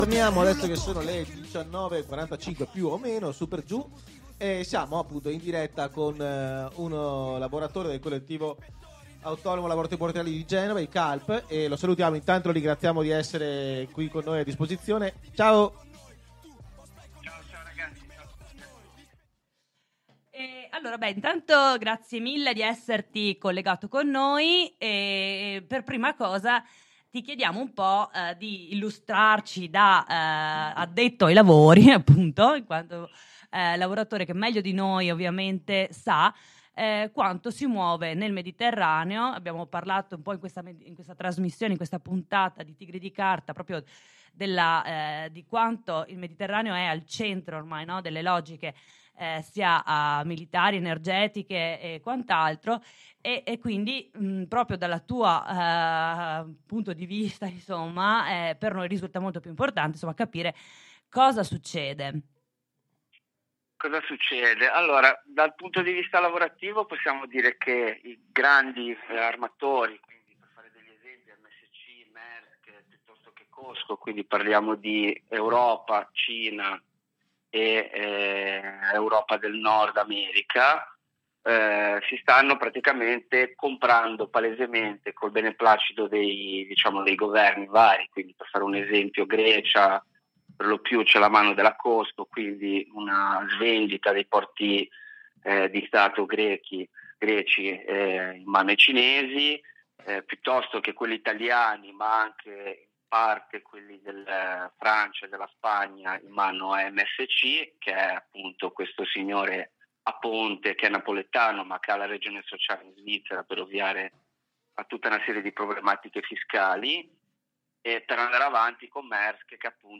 Intervista con un lavoratore del Collettivo Autonomo Lavoratori Portuali di Genova CALP sulla battaglia che da anni portano avanti contro il traffico di armi